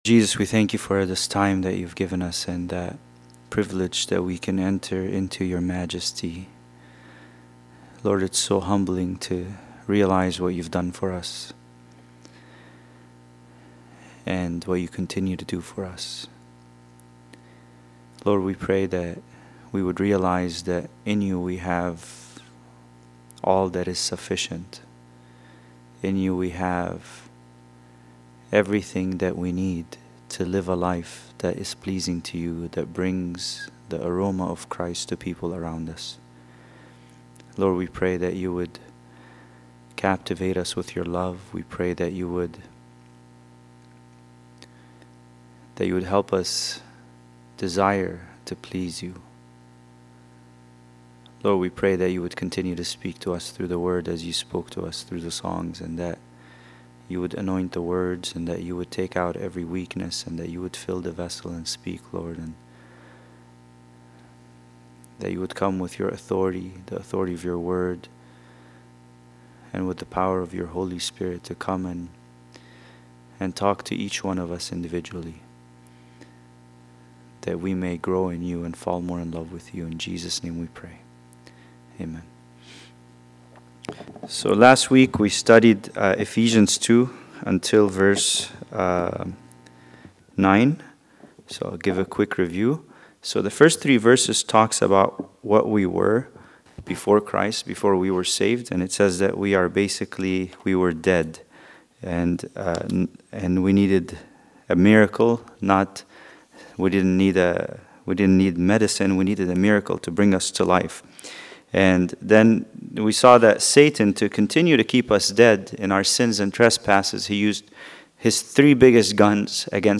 Bible Study: Ephesians 2:10